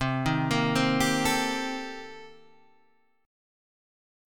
Cm13 chord {8 6 8 8 8 5} chord